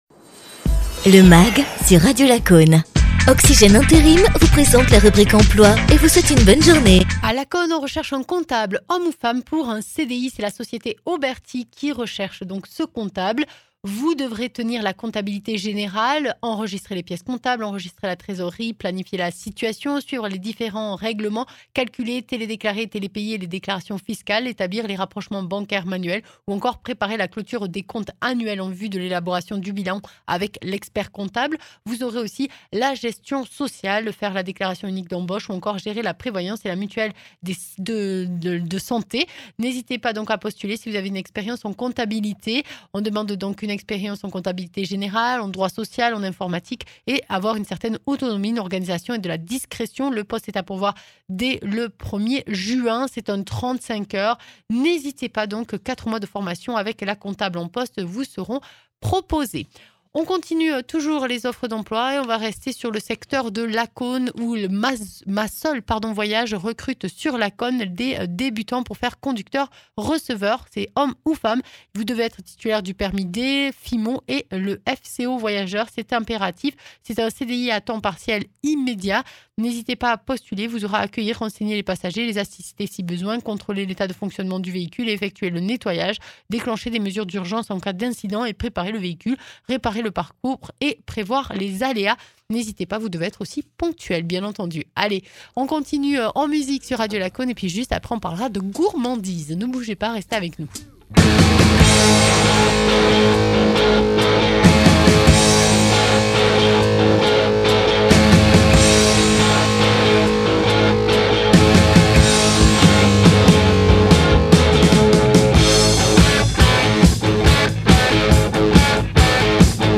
pâtissier chocolatier